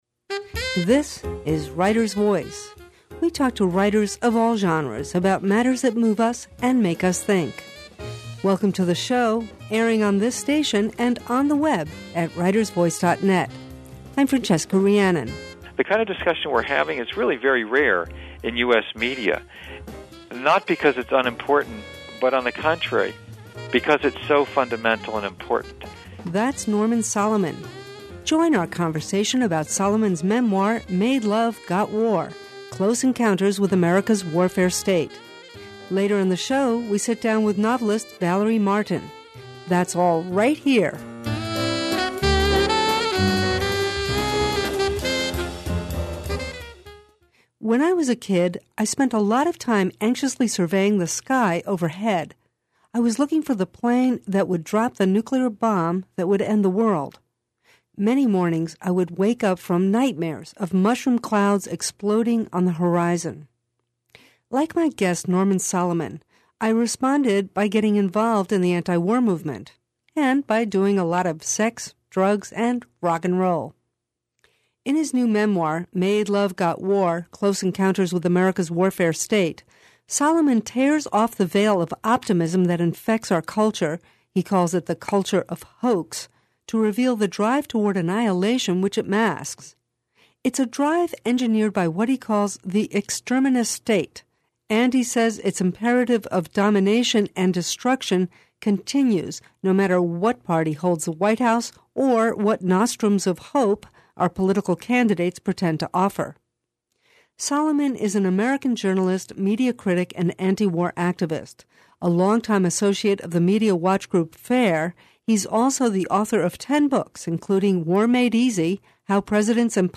Writers Voice— in depth conversation with writers of all genres, on the air since 2004.